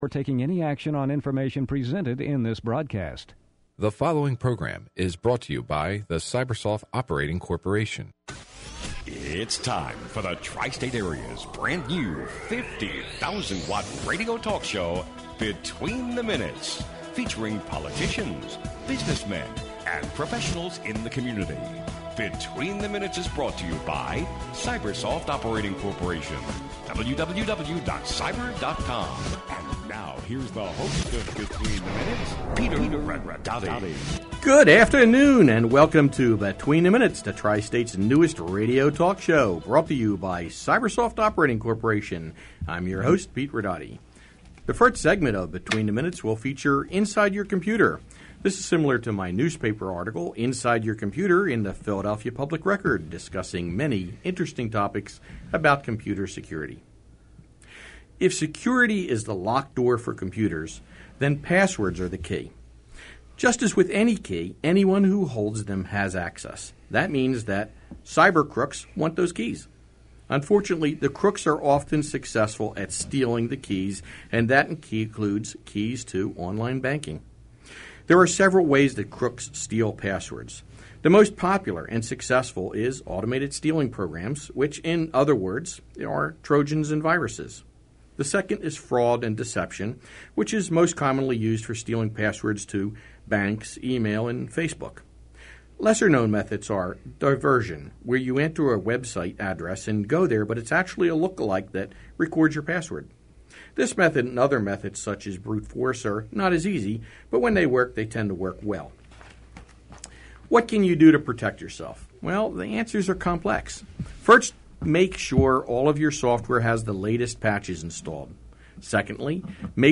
Between the Minutes welcomes the Honorable Judge Holly J. Ford and David Oh to the show. Judge Holly serves as a Domestic Relations Judge for the City of Philadelphia under the Family Court Division.